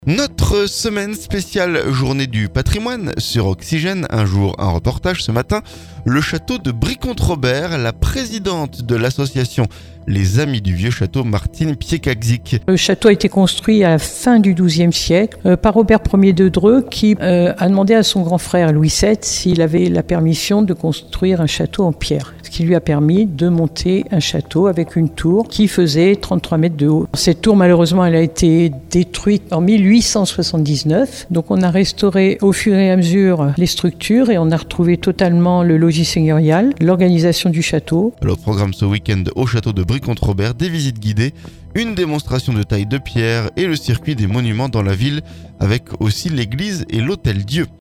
Un jour, un reportage.